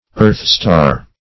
Earthstar \Earth"star`\, n. (Bot.)